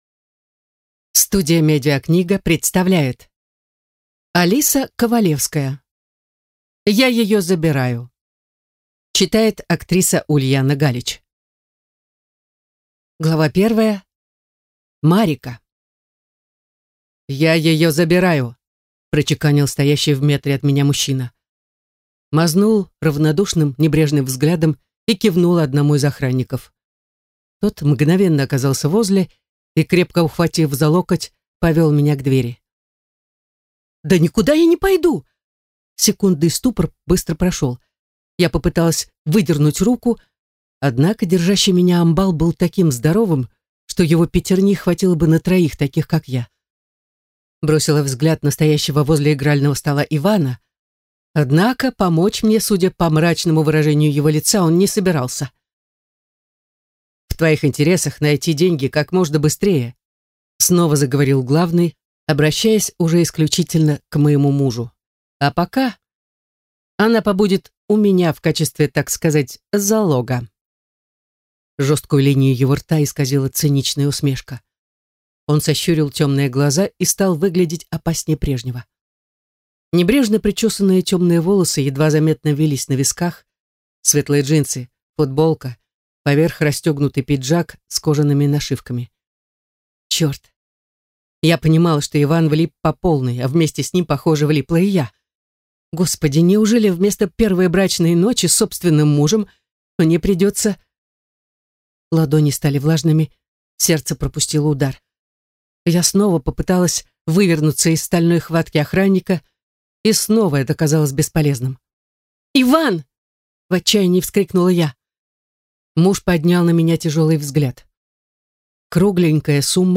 Аудиокнига Я её забираю | Библиотека аудиокниг
Прослушать и бесплатно скачать фрагмент аудиокниги